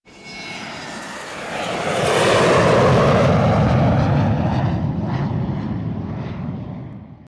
Airplane Takeoff
Airplane Takeoff.wav